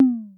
added sound to pong and fixed some bugs
lost.wav